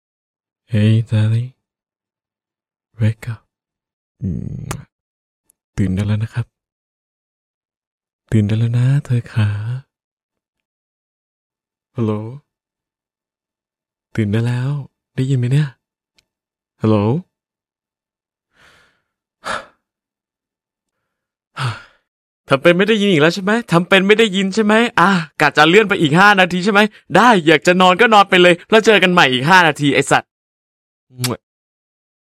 หมวดหมู่: เสียงเรียกเข้า